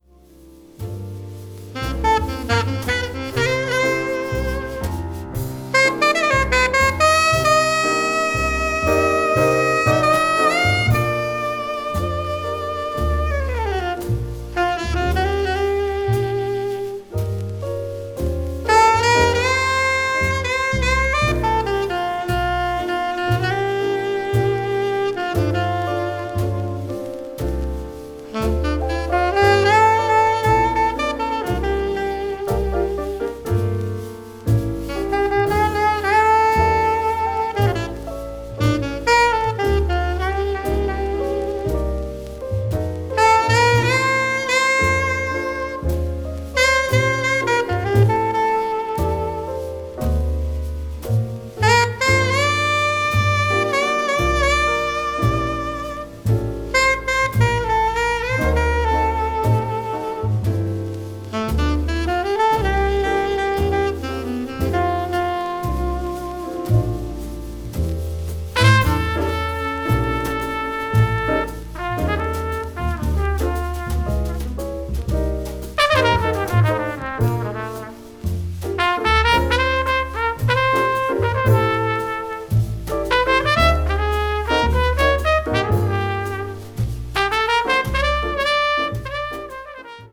afro cuban jazz   hard bop   modern jazz